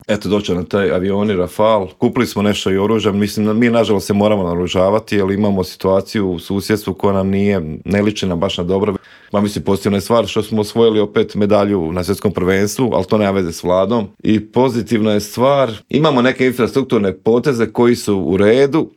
ZAGREB - U Intervjuu Media servisa gostovao je Mario Radić iz Domovinskog pokreta koji se osvrnuo na optužbe premijera Andreja Plenkovića da iza prosvjeda svinjogojaca stoji upravo njegova stranka, otkrio nam s kim bi DP mogao koalirati nakon parlamentarnih izbora, a s kim nikako i za kraj rezimirao 2023. godinu koja lagano ide kraju.